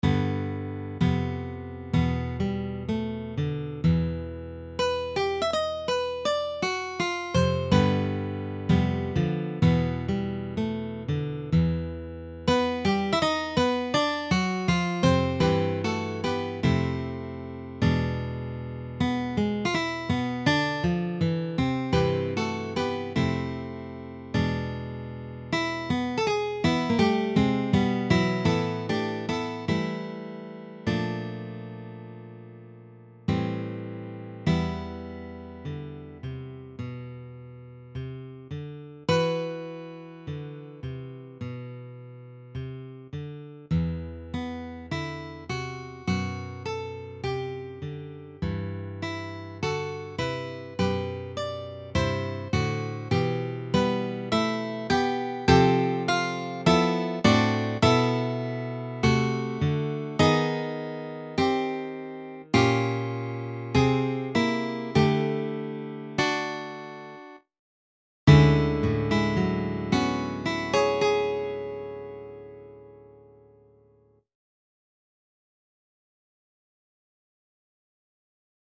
for Guitar Quartet
The main themes return, briefly, in the final movement